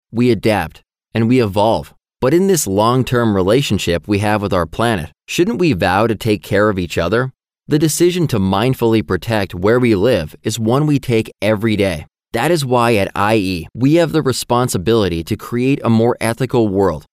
locutor de Estados Unidos.